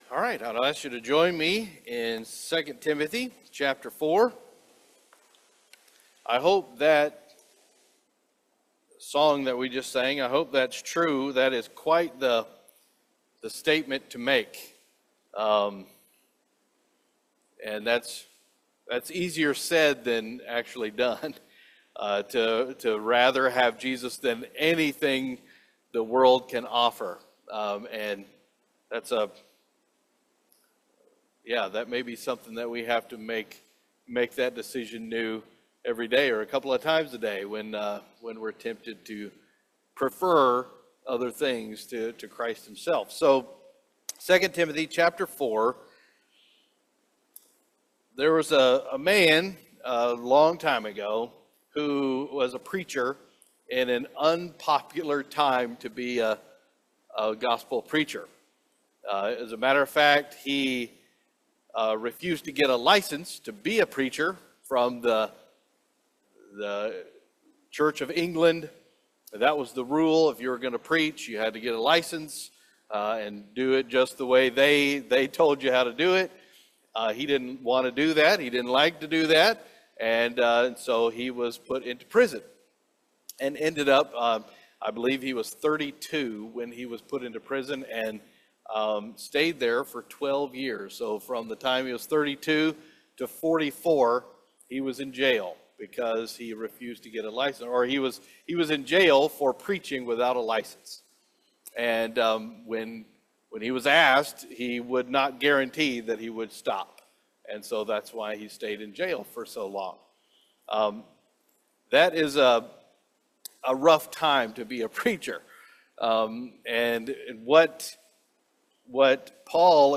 Our latest Sunday morning worship service Messages from God’s word